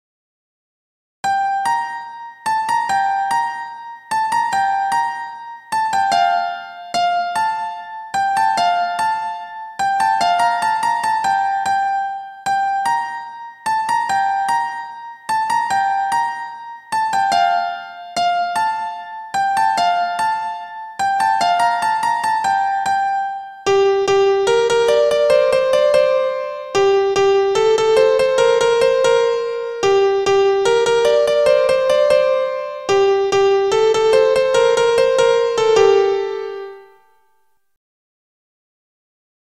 Piano Melody